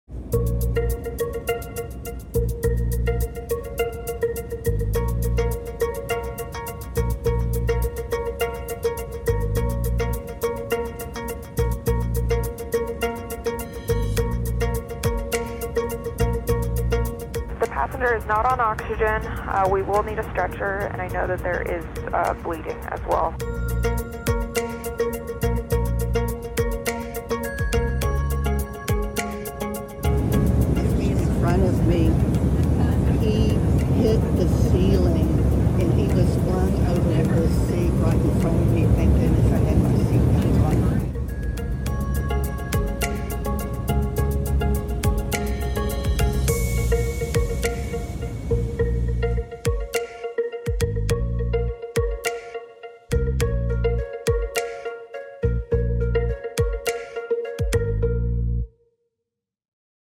Severe turbulence caused a Houston bound sound effects free download By cnn 8 Downloads 2 hours ago 60 seconds cnn Sound Effects About Severe turbulence caused a Houston-bound Mp3 Sound Effect Severe turbulence caused a Houston-bound flight operated by SkyWest to drop 4,000 feet in the span of one minute, injuring two passengers. Cell phone video captured the moments following the incident.